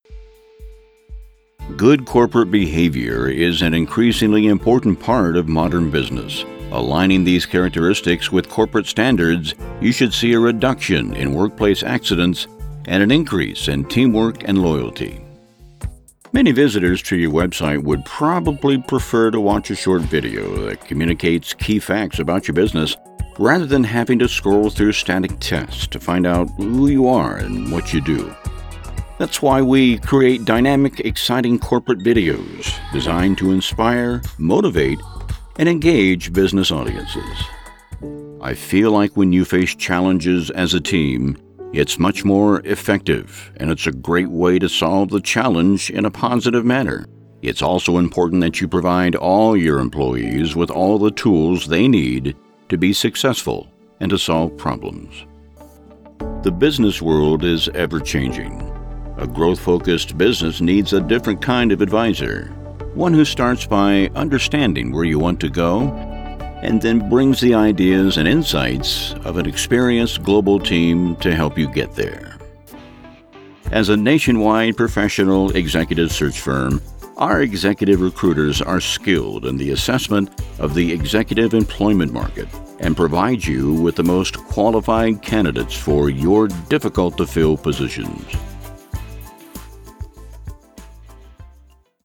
Classic Corporate
southern
Senior